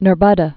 (nər-bŭdə)